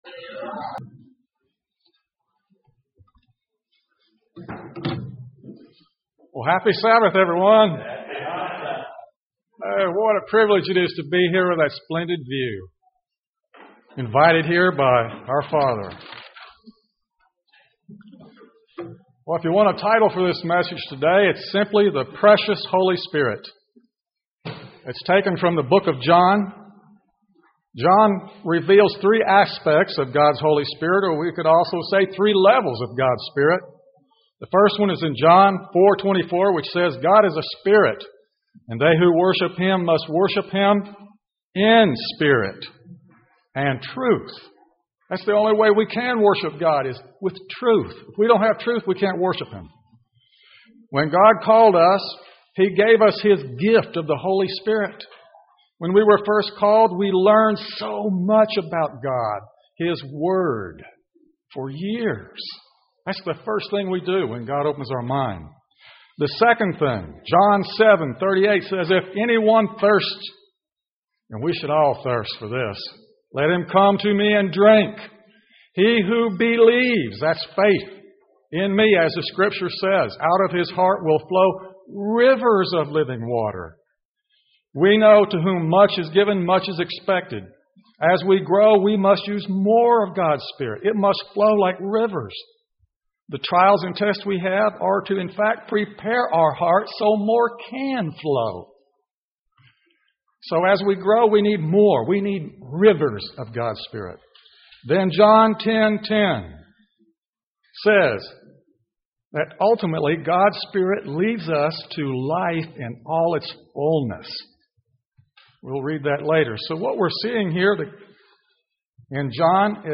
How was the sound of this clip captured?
Given in Birmingham, AL Gadsden, AL Huntsville, AL